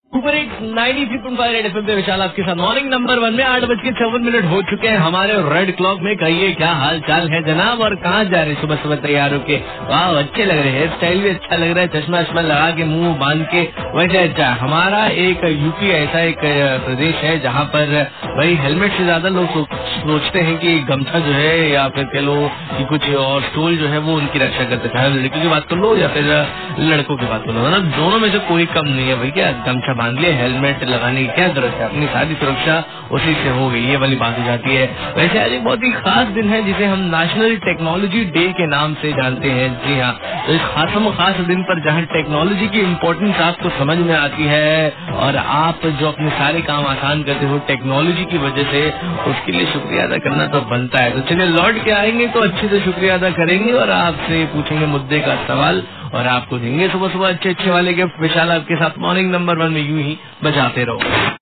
rj about uttar pradesh and technology day